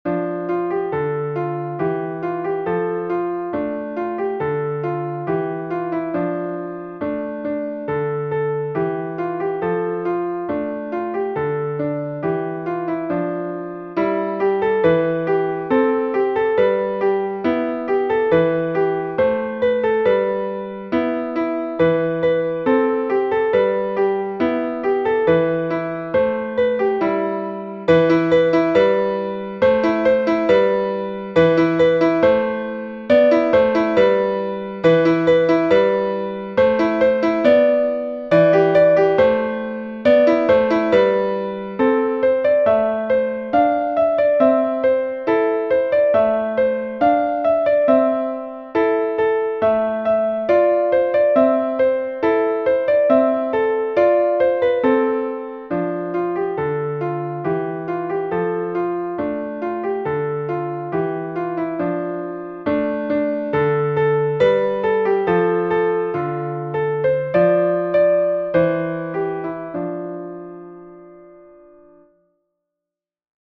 easy and engaging piano solos
Instructional, Medieval and Renaissance